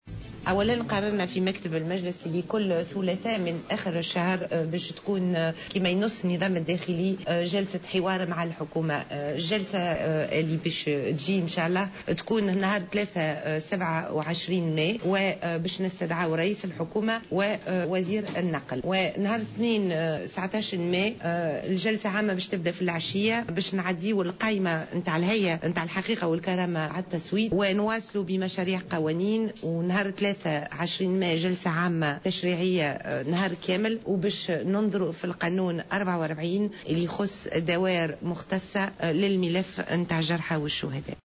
تحدثت كريمة صويد النائبة المكلفة بالإعلام بالمجلس الوطني التأسيسي في تصريح لجوهرة أف أم عن برنامج عمل المجلس في الفترة القادمة بعد الانتهاء من المصادقة على الدستور والقانون الانتخابي.